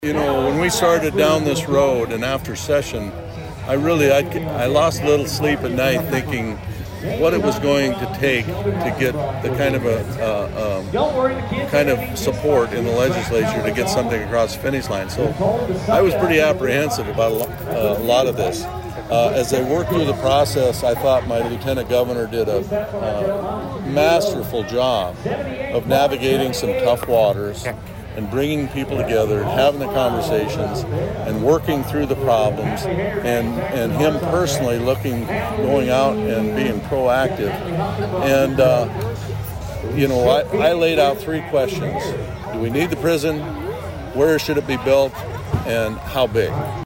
Prior to that Governor Rhoden did an interview with HubCityRadio to address a variety of topics.